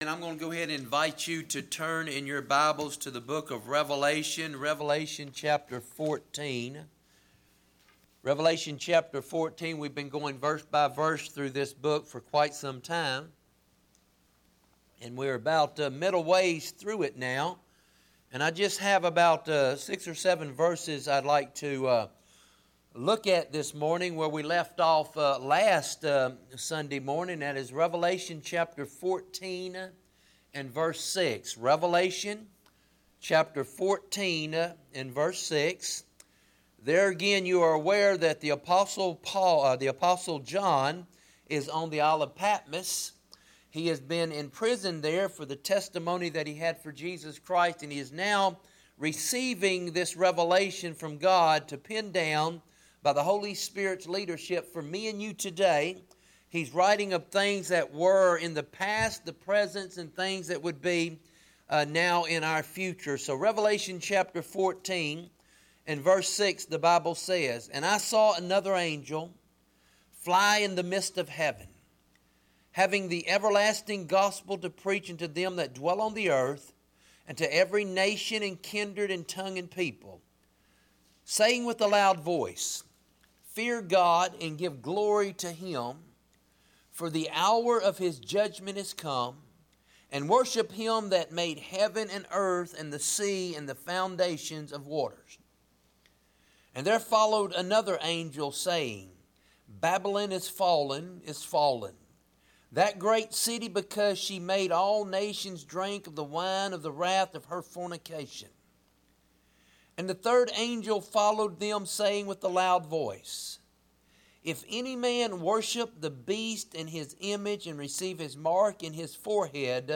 Bible Text: Revelation 14:6-13 | Preacher